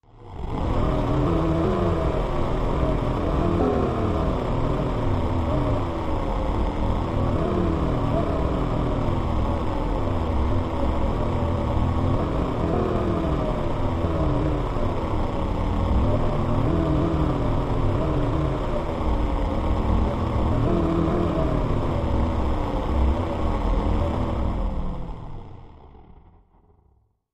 Frozen Time, Machine, Buffer, Low Wind, Electric, Engine